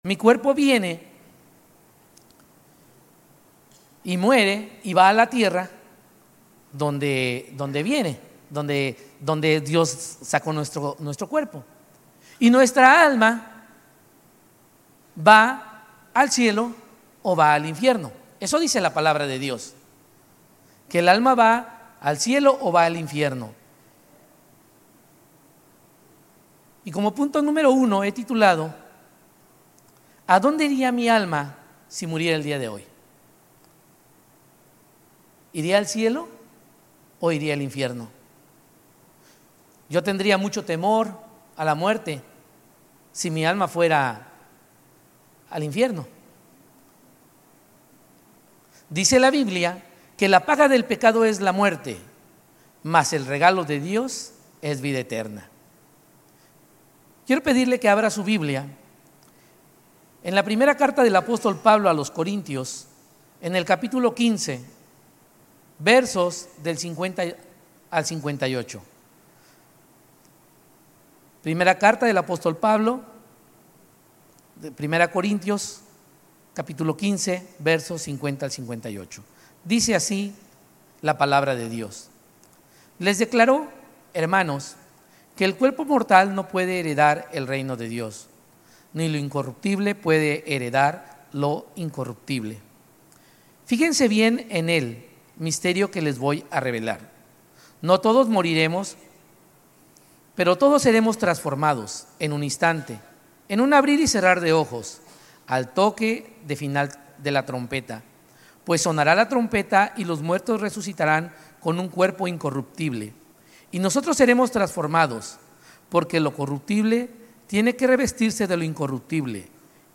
Sermones Grace Español 5_25 Grace Espanol Campus May 26 2025 | 00:32:38 1x 00:00 / 00:32:38 Subscribe Share RSS Feed Share Link Embed